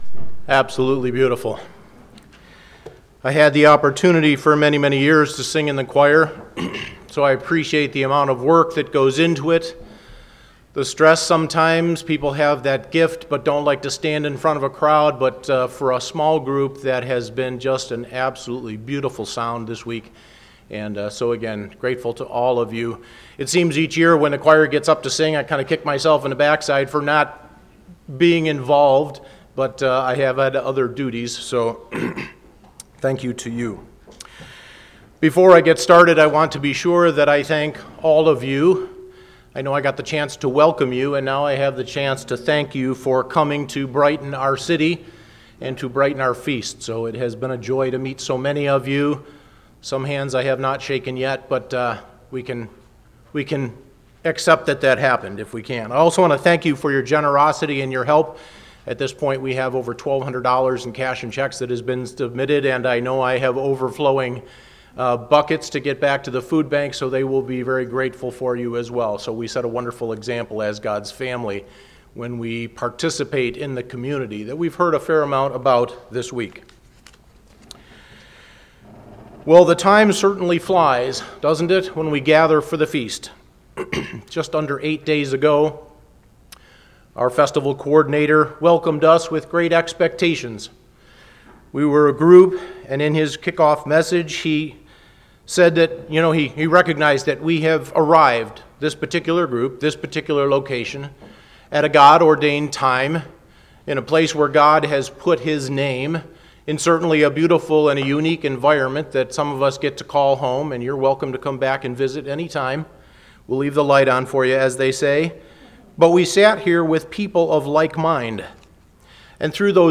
This sermon was given at the Anchorage, Alaska 2021 Feast site.